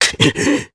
Riheet-Vox_Damage_jp_01.wav